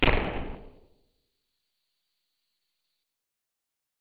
Stump.wav